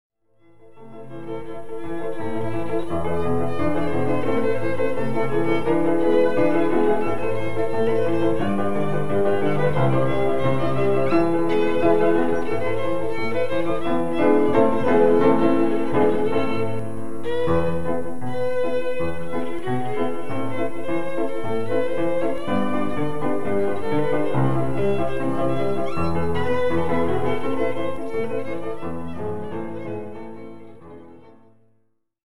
CSARDAS interprčte ŕ la façon tzigane des styles de musique divers comme:
- chansons et danses hongroises: